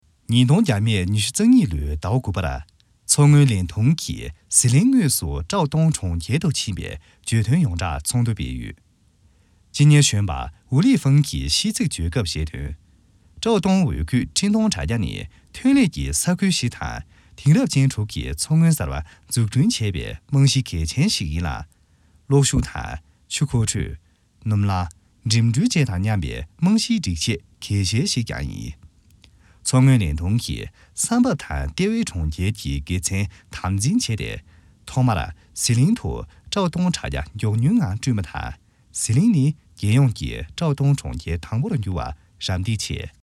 拉萨藏语-推荐